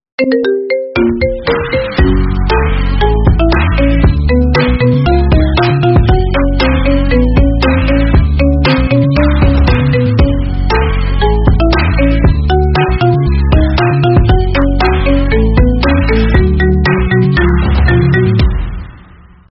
Kategori: Nada dering
Anda pasti akan menyukai nada dering remix ini.